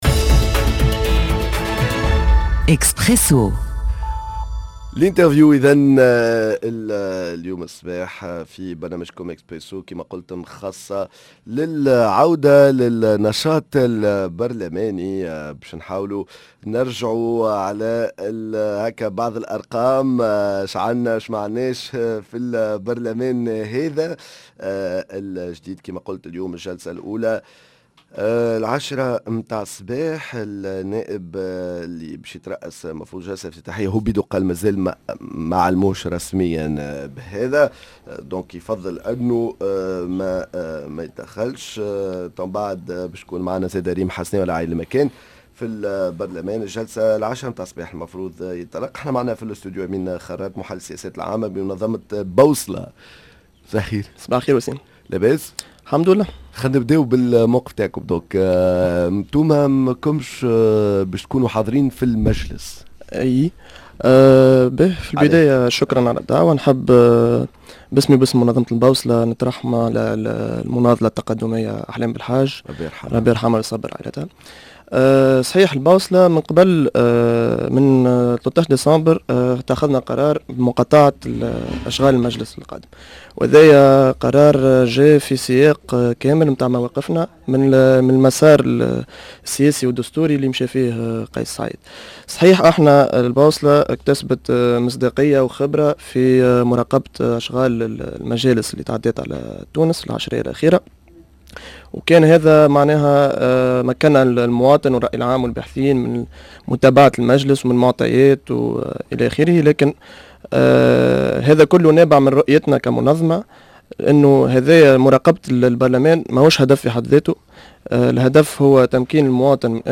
L’interview البوصلة الجلسة الافتتاحية لمجلس النواب الجديد